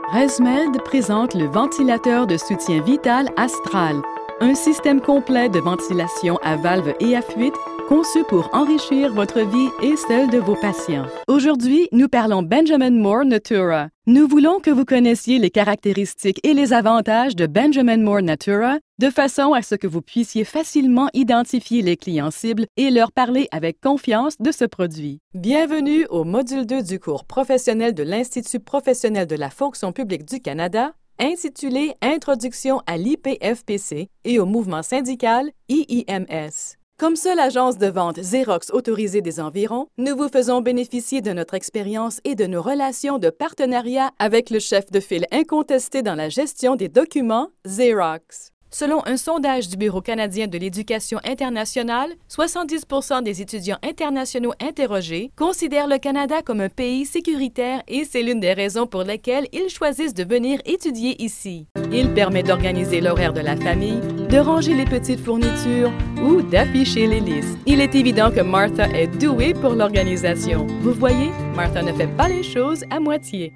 Franko Kanadier)
Native Voice-Samples
Kommerzielle Demo
Unternehmensvideos